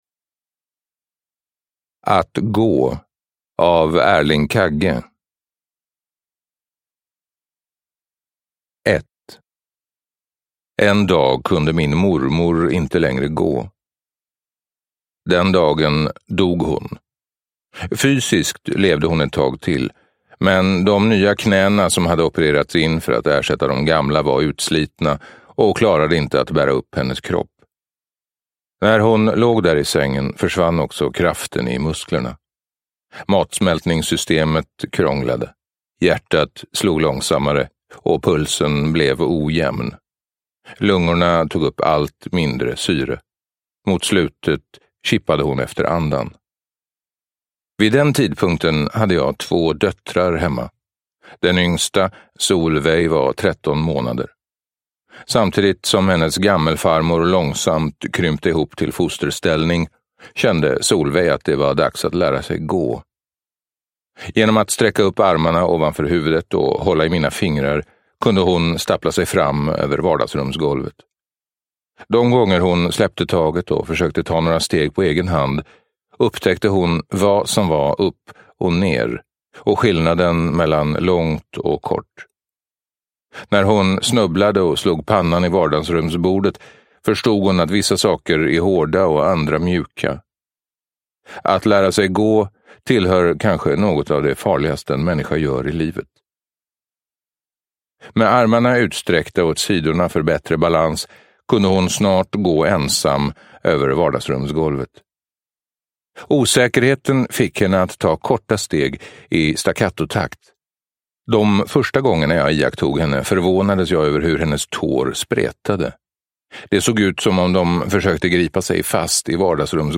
Att gå : ett liv i rörelse – Ljudbok – Laddas ner